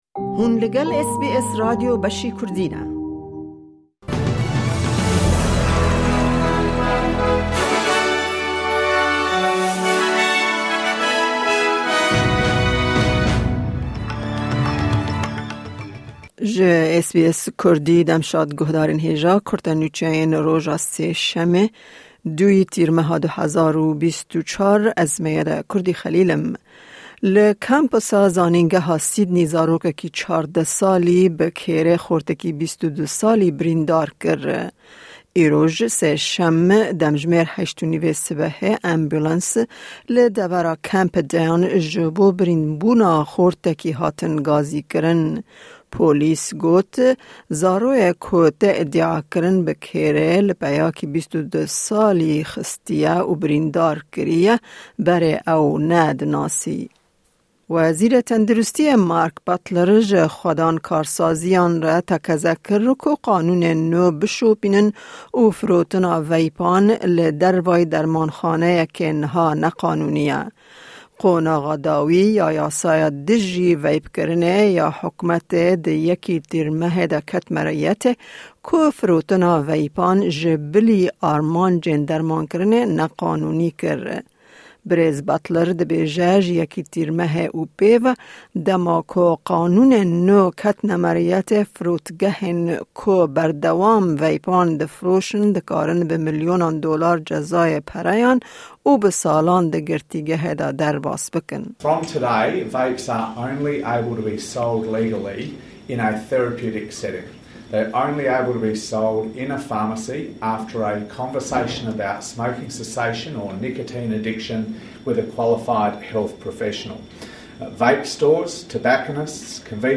Kurte Nûçeyên roja Sêşemê 2î Tîrmeha 2024